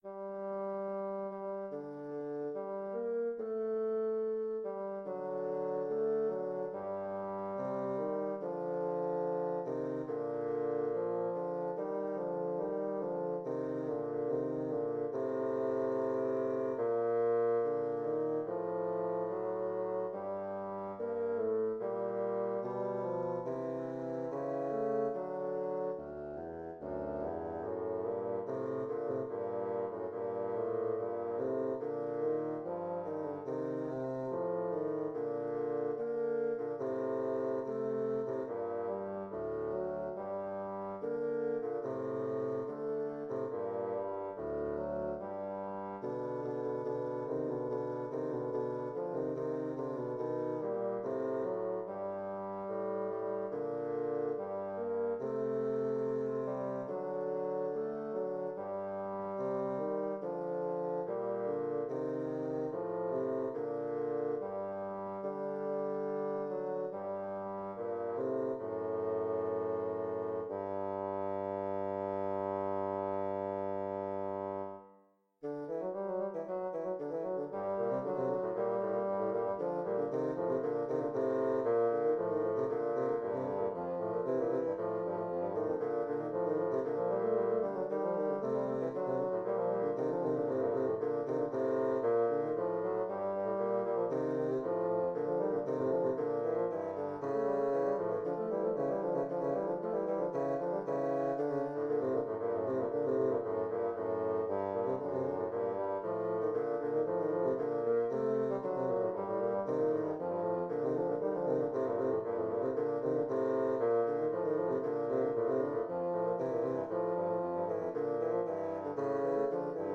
Intermediate bassoon duet
Instrumentation: Bassoon duet